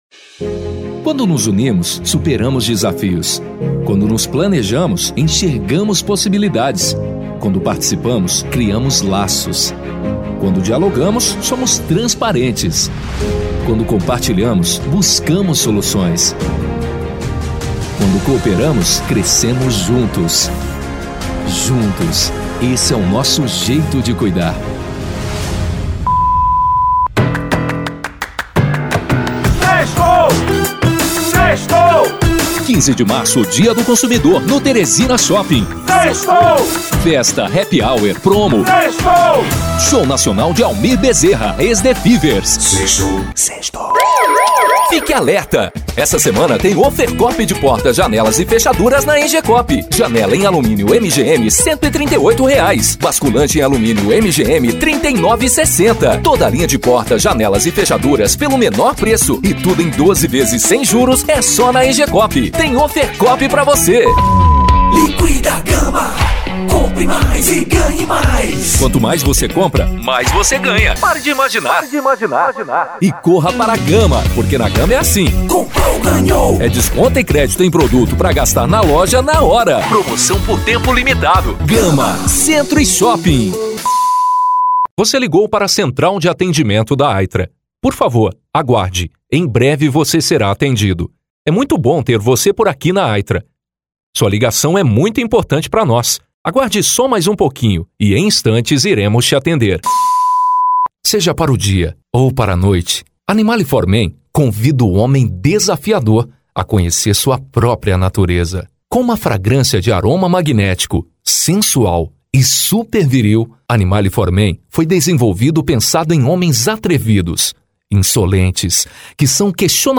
VOZES MASCULINAS
Estilos: Padrão Emotivo